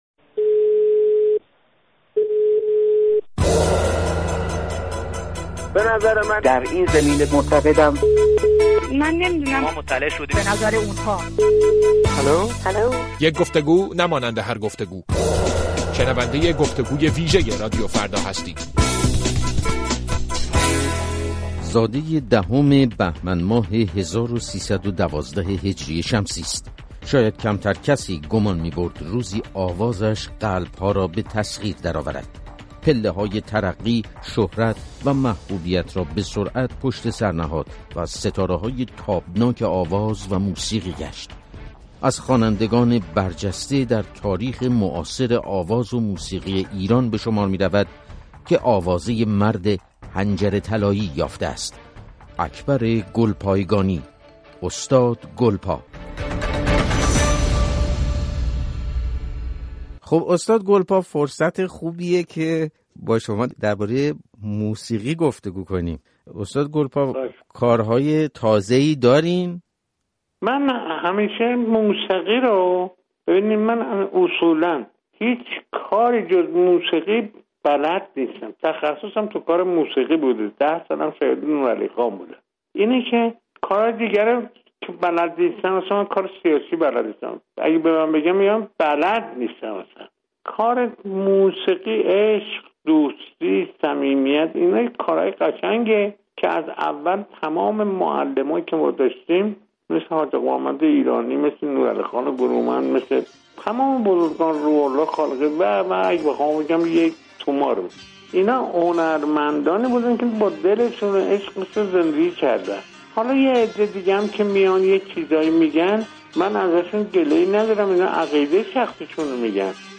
مصاحبه اختصاصی با گلپا؛ موسیقی ایرانی فقط من و ما نیست
مصاحبه با اکبر گلپایگانی